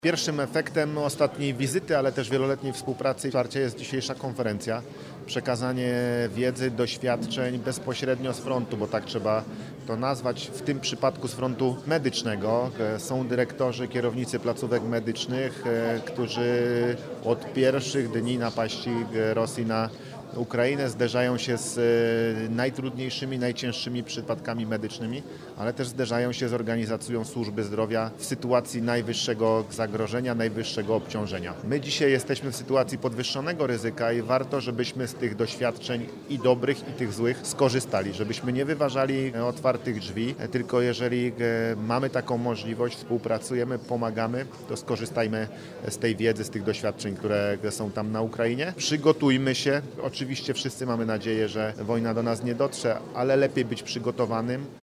O tym rozmawiali eksperci podczas konferencji na Politechnice Wrocławskiej pod hasłem: „Bezpieczeństwo i funkcjonowanie podmiotów leczniczych w sytuacjach kryzysowych na podstawie doświadczeń Ukrainy. Zabezpieczenie medyczne wojsk na potrzeby obronne w Polsce”.
Paweł Gancarz, marszałek Województwa Dolnośląskiego, podkreślił, że współpraca z Ukrainą ma wieloletni charakter, jednak w ostatnich latach — w obliczu wojny — nabrała szczególnego znaczenia.